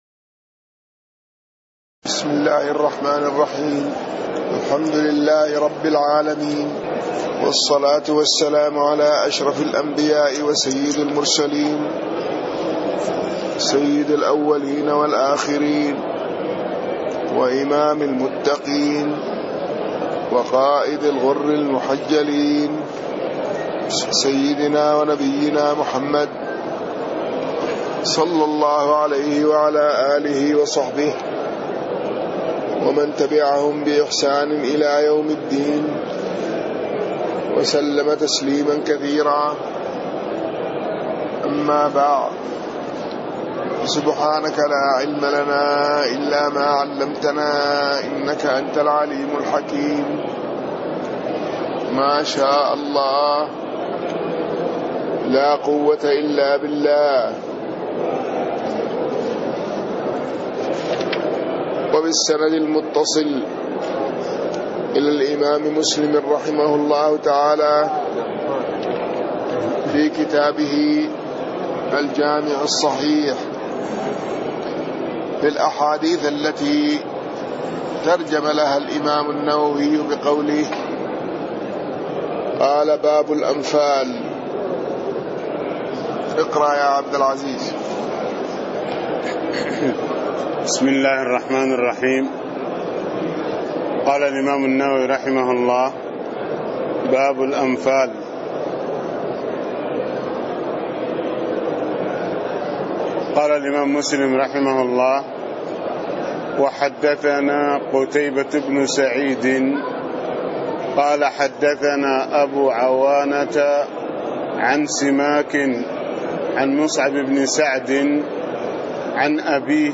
تاريخ النشر ٥ رمضان ١٤٣٥ هـ المكان: المسجد النبوي الشيخ